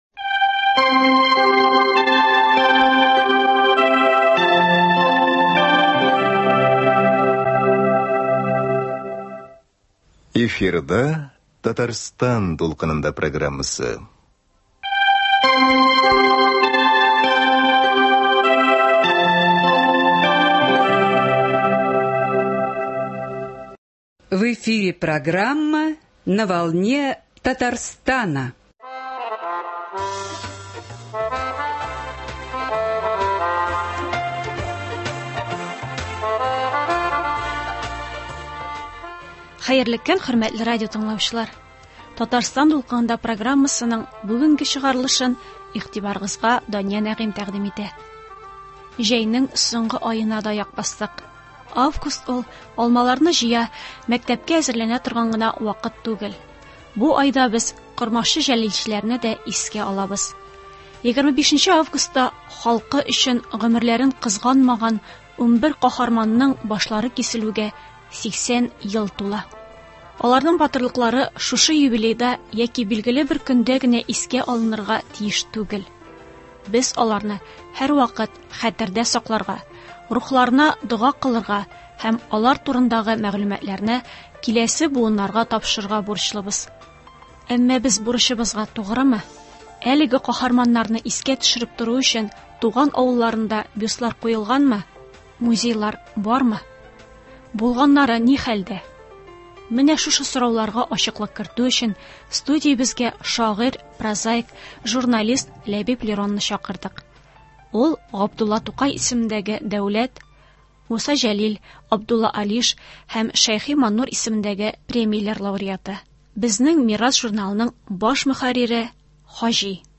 студиябезгә шагыйрь, прозаик, журналист